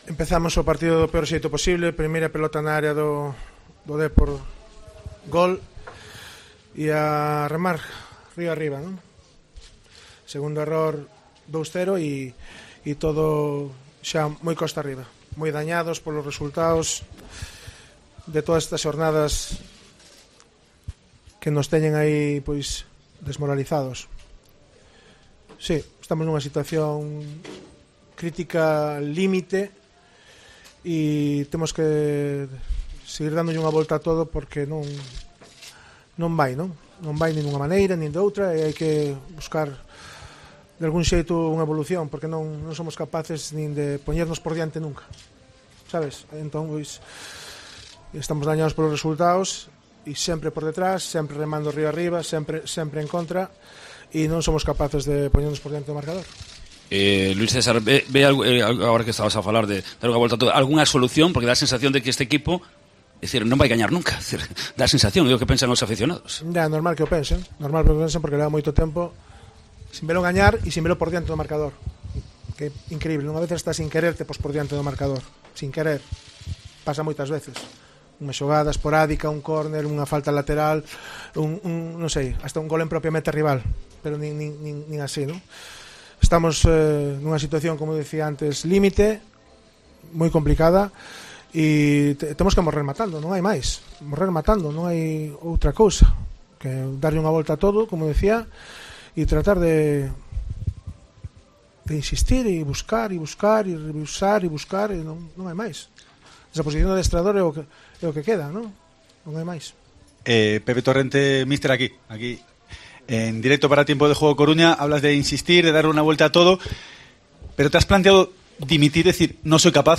AUDIO: Escucha aquí al míster del Deportivo de la Coruña tras la derrota en El Toralín 2-0 ante la Deportiva Ponferradina